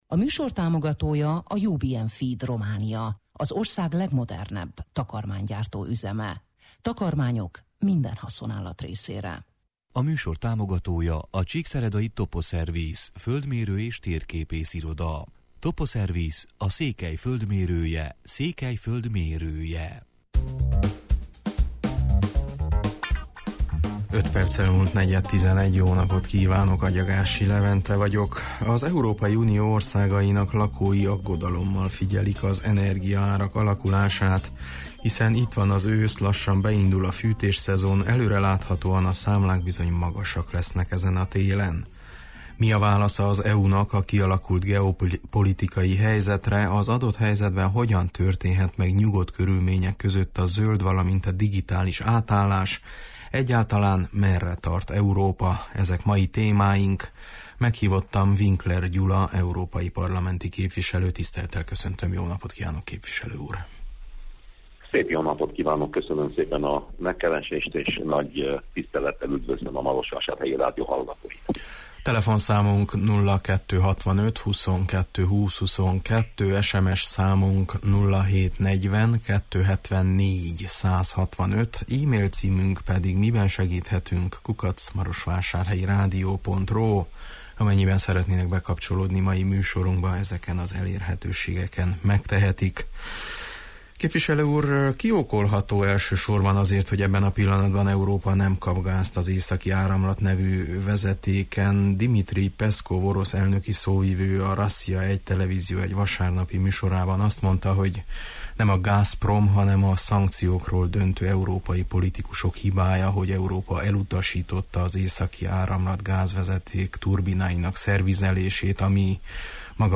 Meghívottam Winkler Gyula európai parlamenti képviselő: